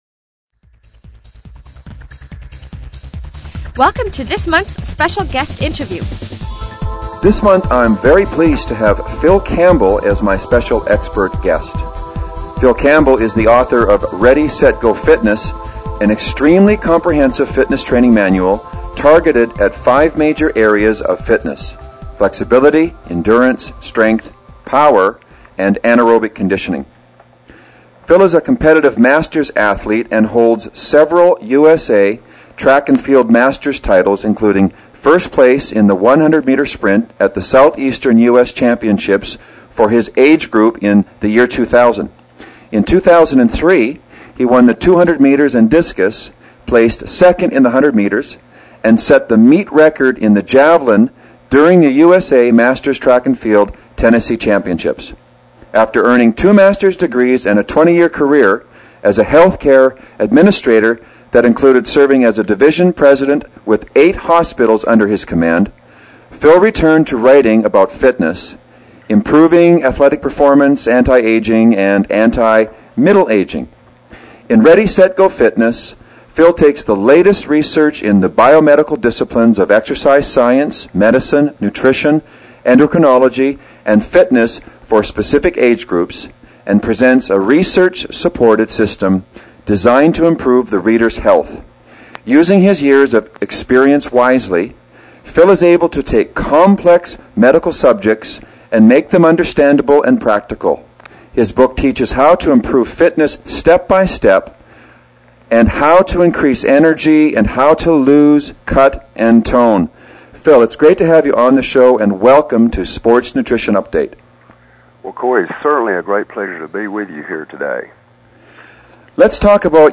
Special Guest Interview